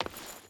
Footsteps
Stone Chain Run 5.ogg